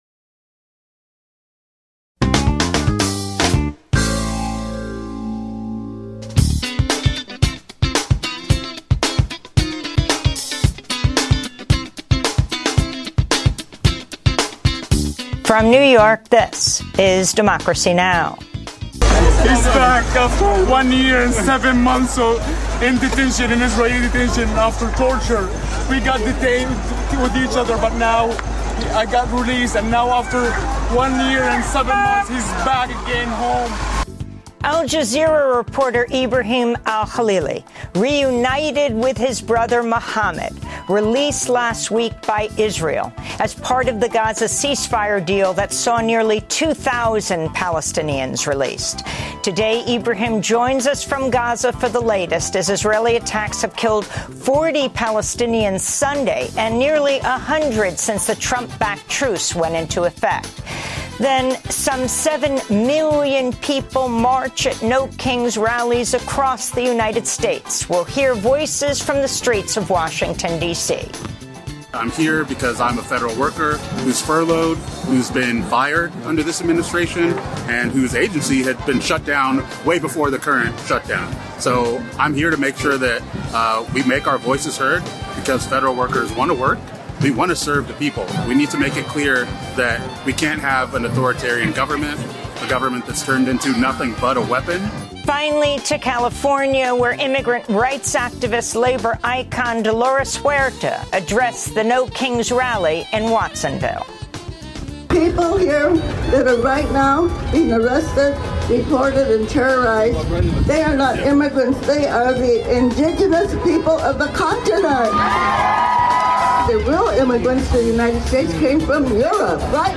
daily, global, independent news hour